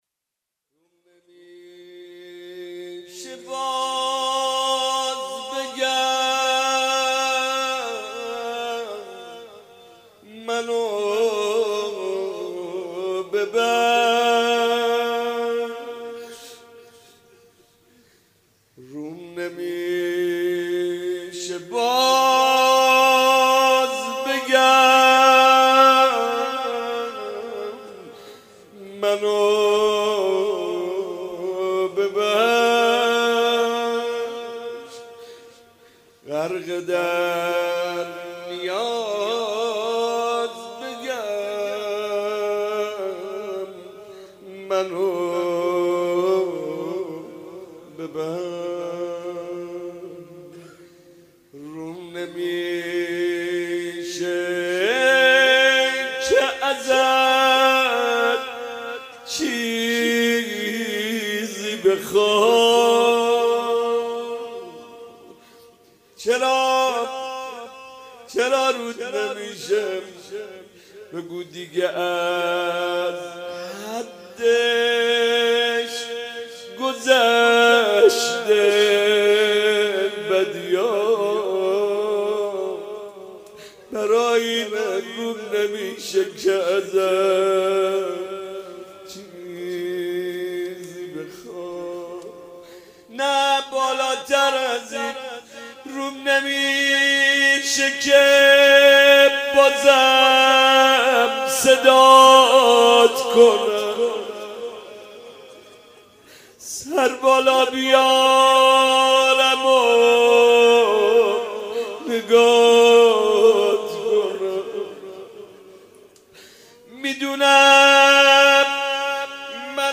مناجات
مناسبت : شب ششم رمضان